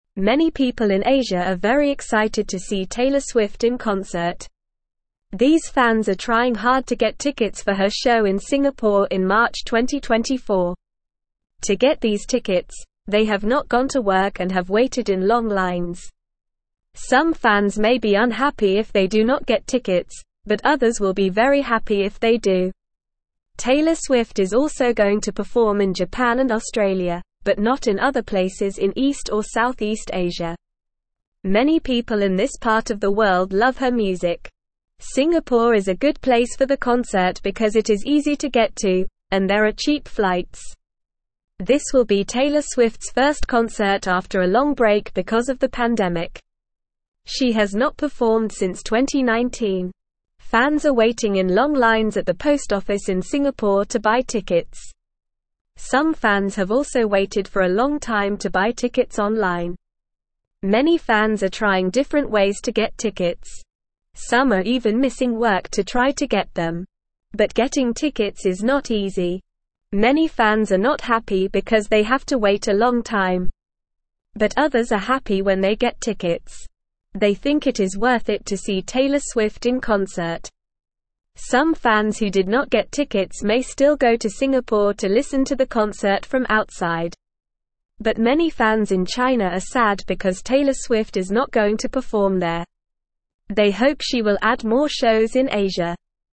Normal
English-Newsroom-Beginner-NORMAL-Reading-Fans-Work-Hard-for-Taylor-Swift-Tickets.mp3